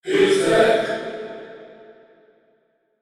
Thinking about this, I considered singing “Hülsbeck”.
As soon as I got home, I recorded my own voice a dozen times, played a bit with panning and reverb and voilà. It’s probably out of tune and certainly ridiculous, but I hope you guys like it, since it was fun to make.